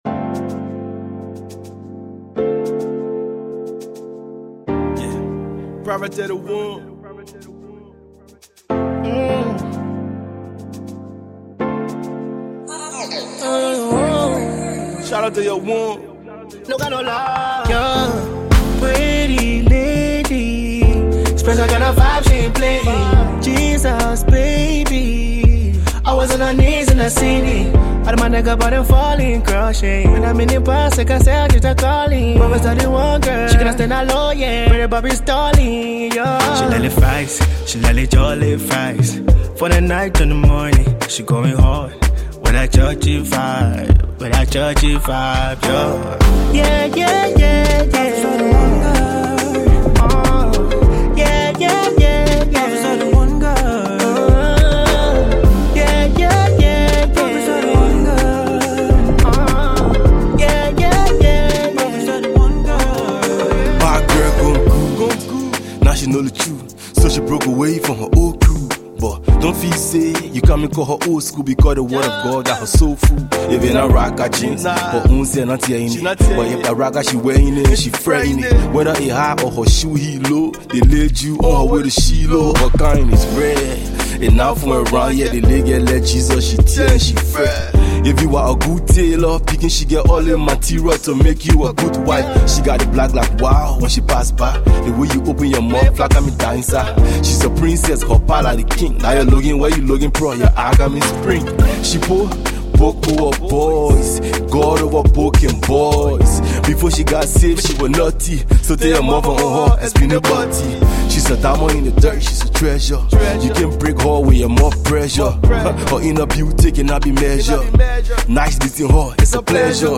/ Gosple, Hip-Co, Hip-Hop, Liberian Music, RnB / By
gospel rapper
an uplifting tribute